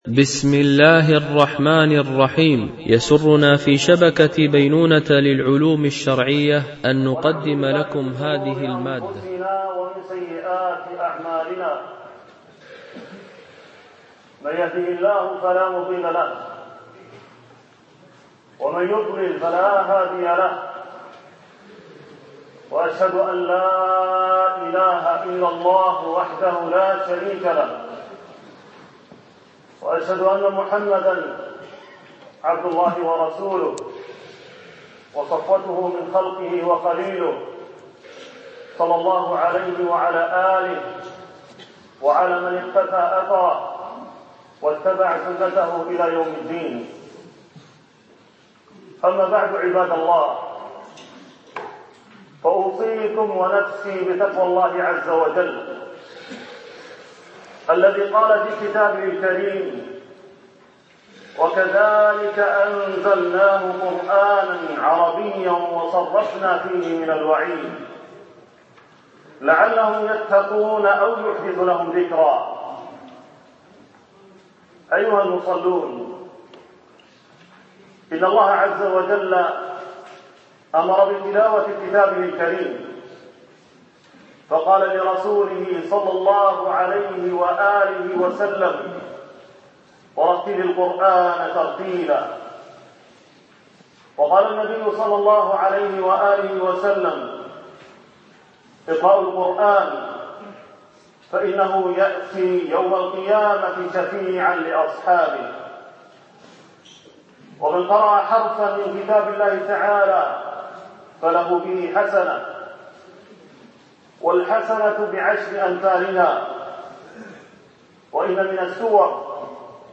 آية الكرسي وفضائلها (خطبة جمعة بمسجد ابن حزم، بمدينة ليريدا - إسبانيا)